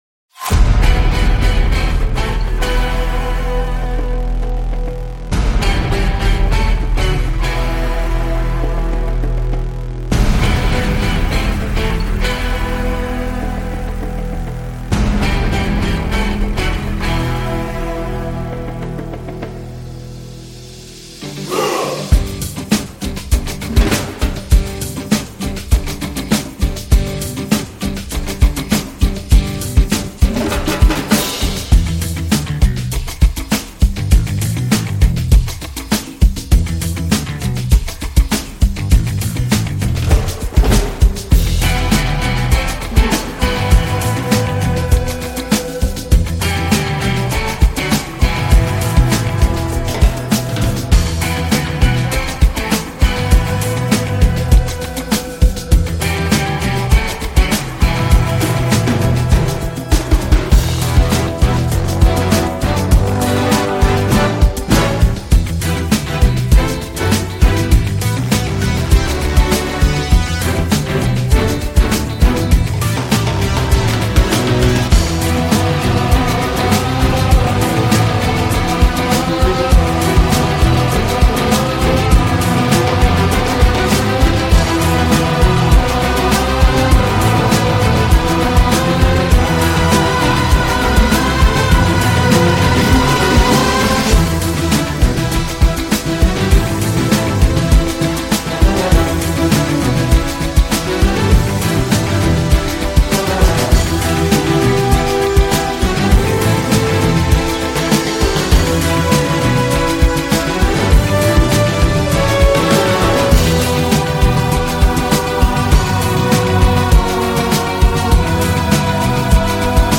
C’est un score zimmerien, comme il en existe à la pelle.